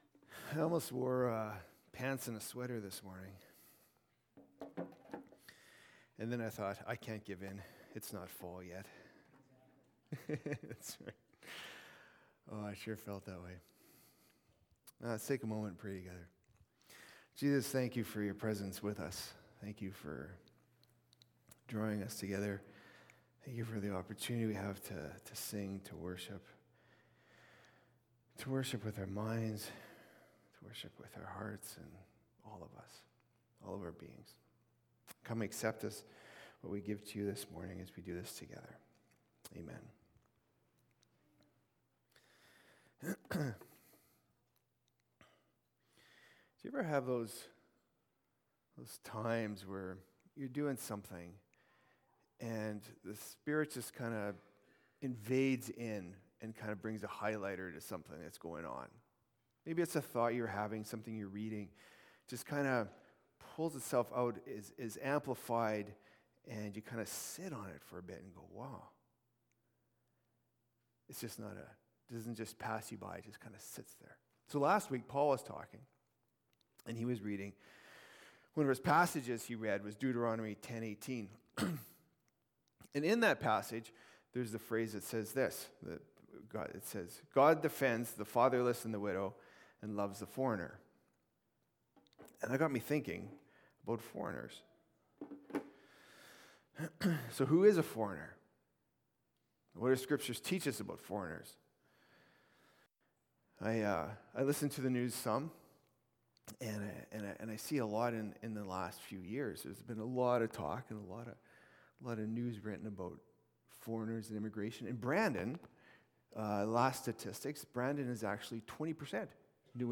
Sermons | Richmond Park Church